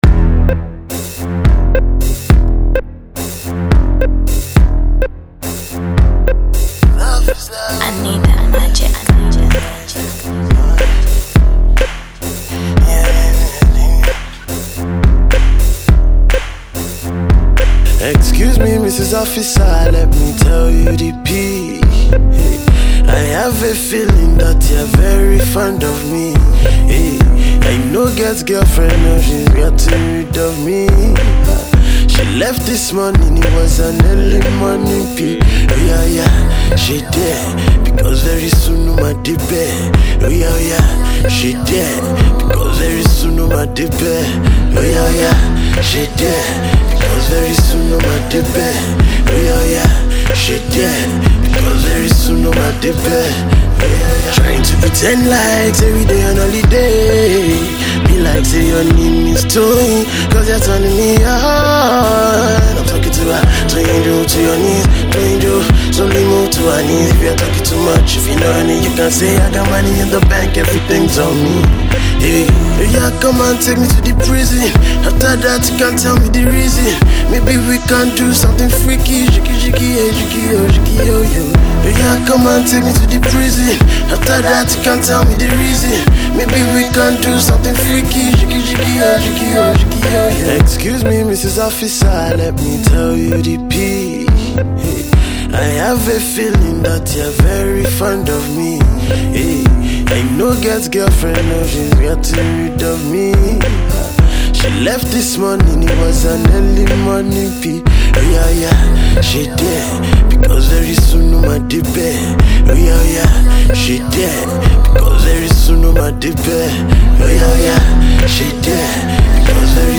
hit-making husky crooner of the Nigerian music industry